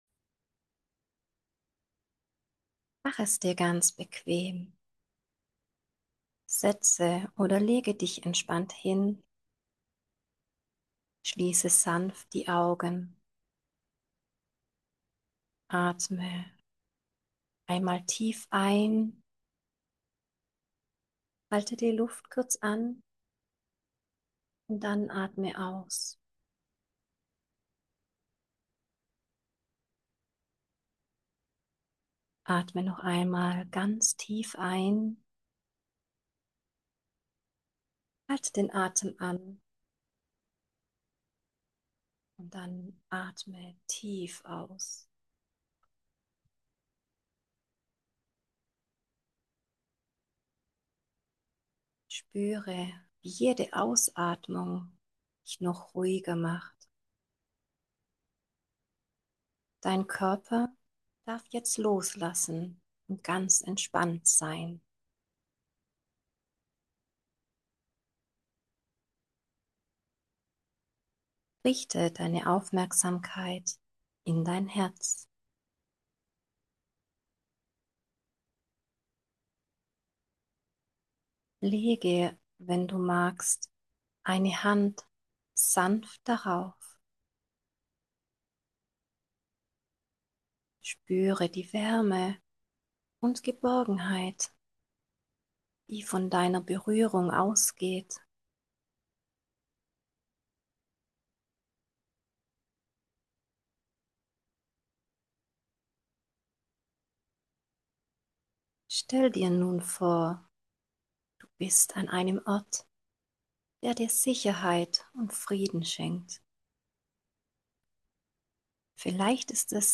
Klangreise – Sich selbst annehmen: Eine energetische Klangreise, deren Fokus darauf ruht, Dich in die Begegnung mit Dir selbst zu führen und Selbstannahme auf zellulärer Ebene zu verankern.